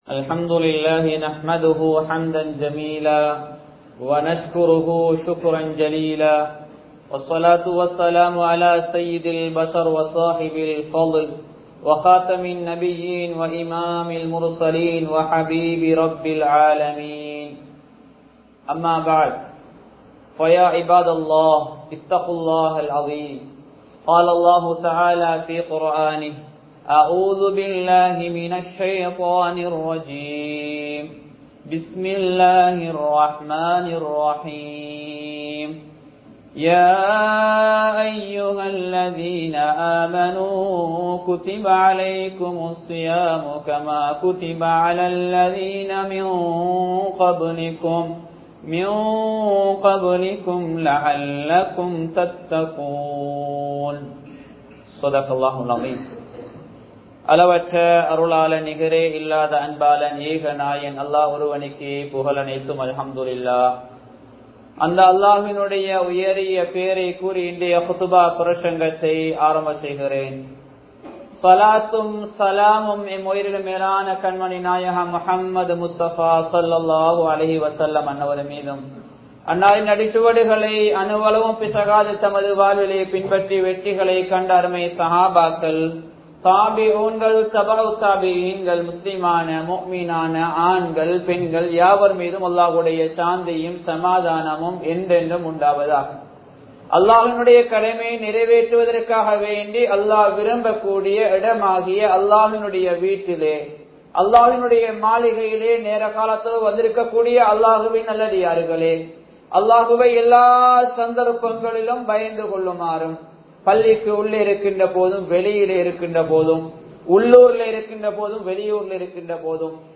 ஸதகாவின் சிறப்புகள் | Audio Bayans | All Ceylon Muslim Youth Community | Addalaichenai
Muhiyadeen Jumua Masjith